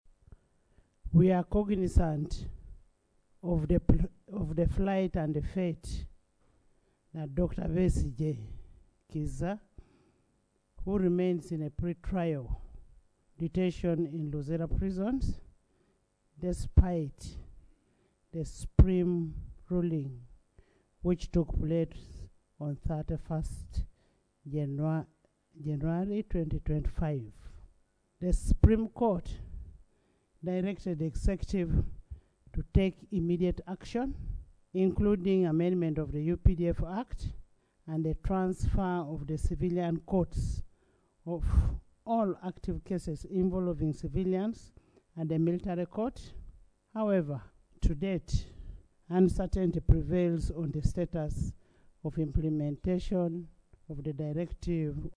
This followed Speaker Anita Among communication to the House where she expressed concern about Besigye’s continued detention at Luzira Maximum Prison, despite a Supreme Court ruling on 31 January 2025 that called for a fair trial before a competent court of law.
AUDIO Speaker Among
Anita Among on Besigye.mp3